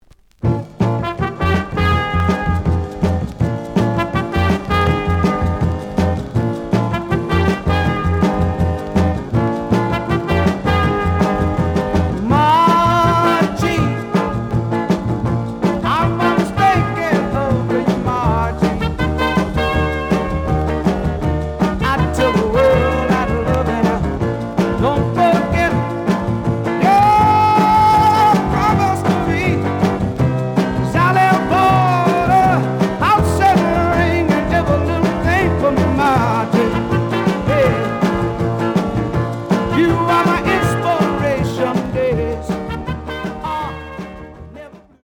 The audio sample is recorded from the actual item.
●Genre: Soul, 60's Soul
Some damage on both side labels. Plays good.)